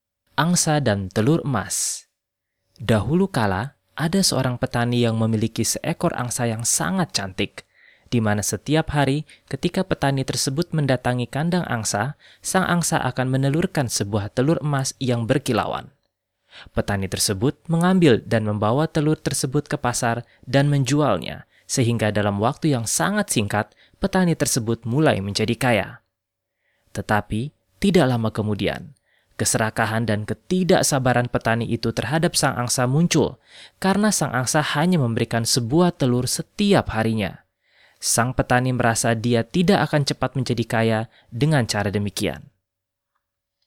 warm voice
Male
Adult (30-50)
A warm and calm sound, best for presentation or e-learning while also fit for announcement, advertisement and tourism.
Audiobooks